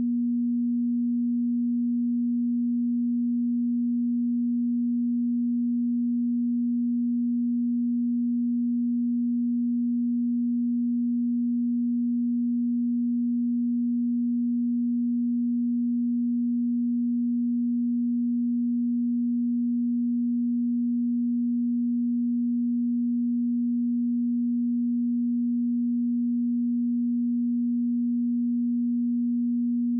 240Hz_-22.dB.wav